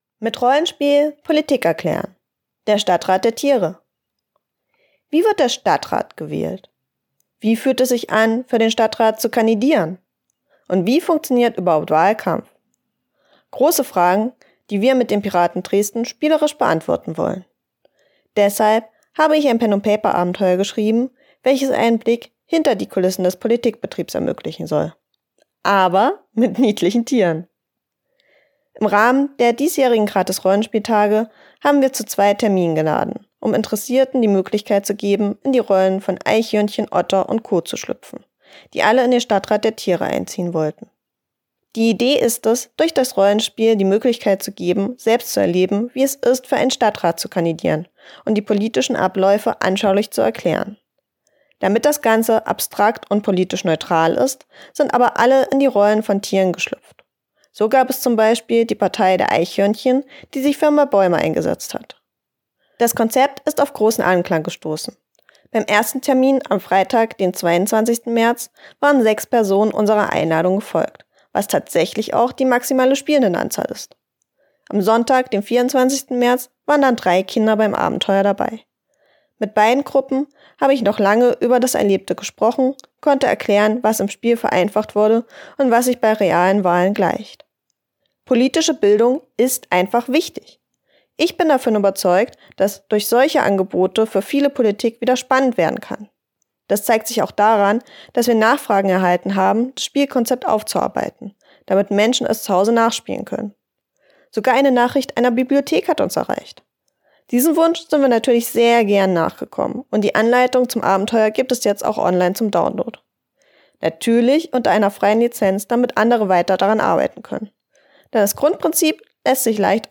Text als Hörfassung: